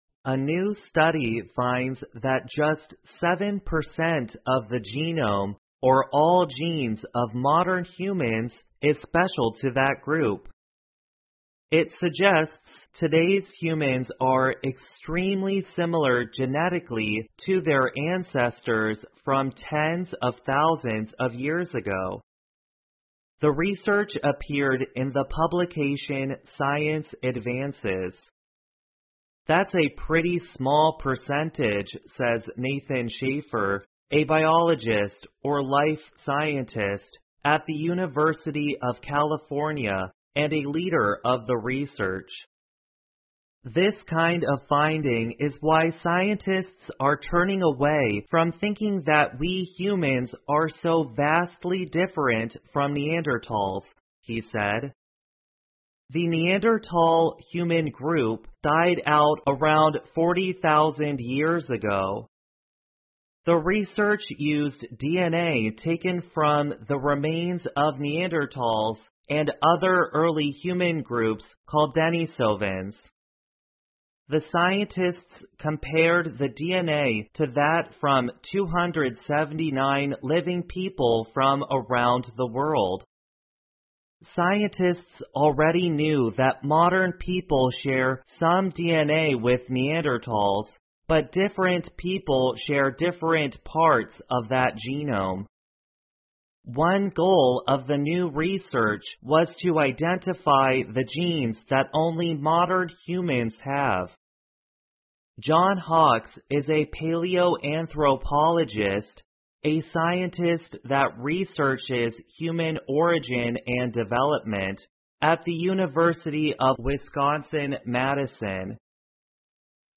VOA慢速英语--研究表明,只有7%的DNA是现代人类独有的 听力文件下载—在线英语听力室